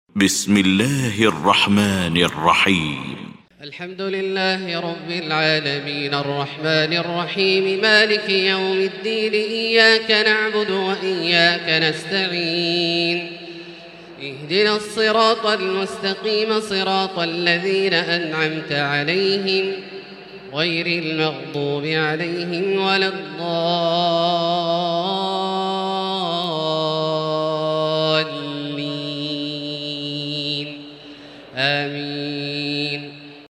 المكان: المسجد الحرام الشيخ: فضيلة الشيخ عبدالله الجهني فضيلة الشيخ عبدالله الجهني الفاتحة